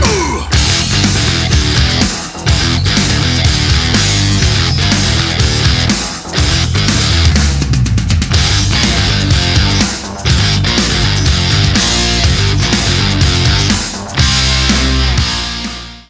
Вы слышите У!... а потом музыка...
и дальше играет РОК.